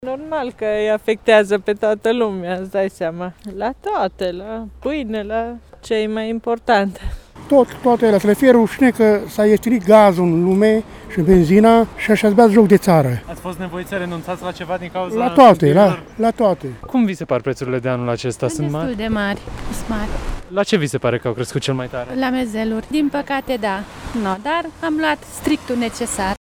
Tg.mureşenii spun că au fost nevoiți să renunțe la unele produse de sărbători din cauza prețurilor piperate , astfel că masa de Paşti va fi mai săracă: